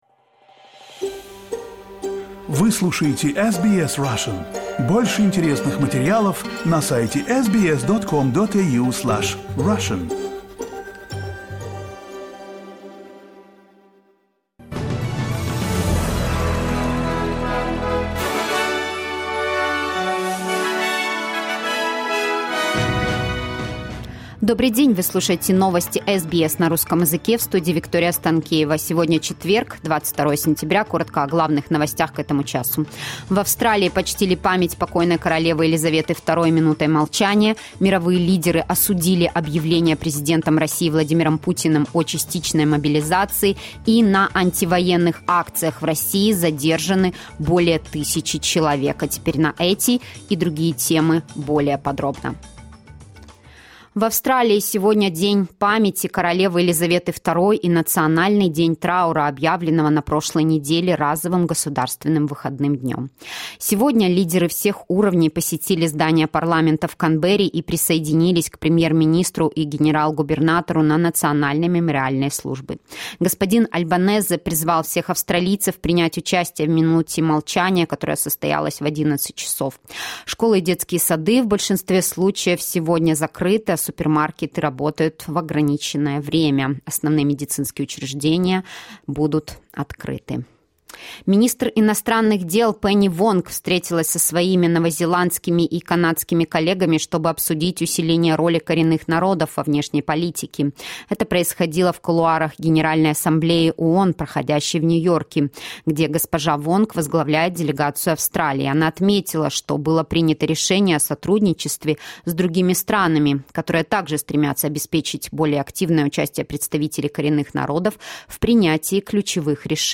SBS news in Russian - 22.09.2022